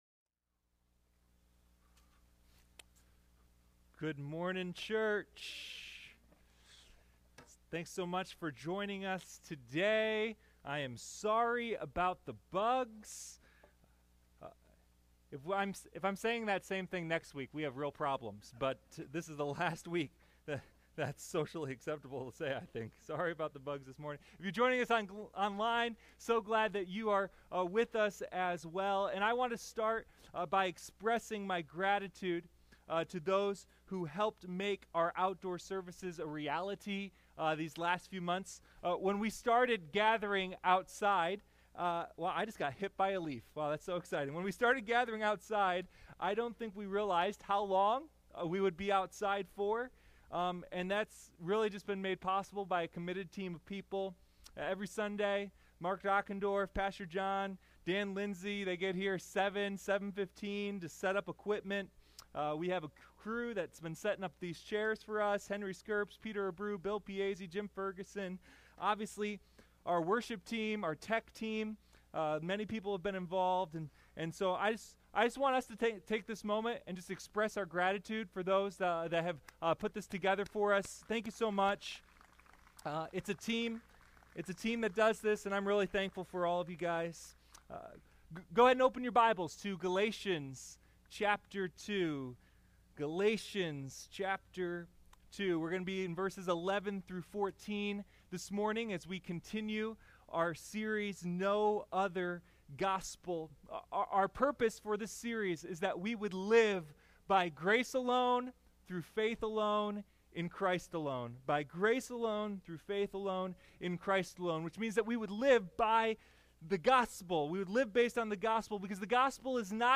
Sunday Morning No Other Gospel: A Study in Galatians